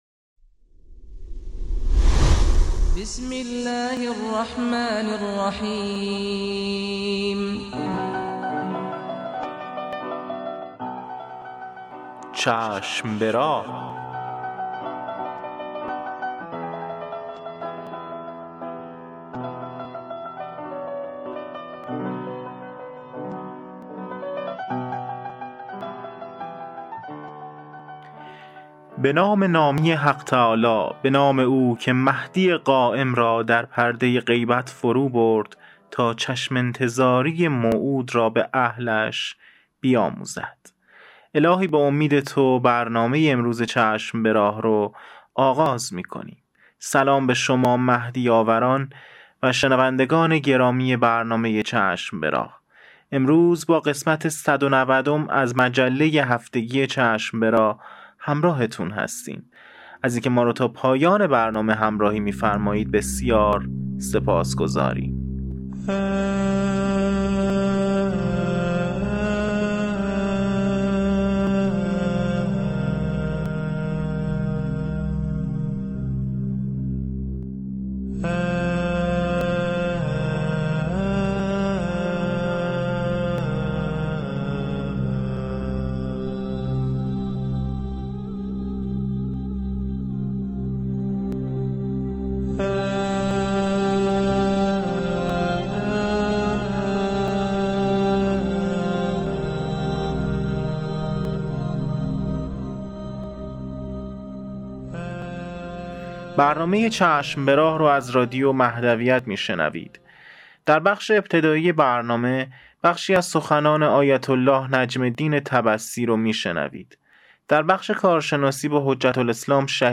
سرود مهدوی
دعای سلامتی امام زمان(عج)(به عربی و فارسی)